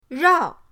rao4.mp3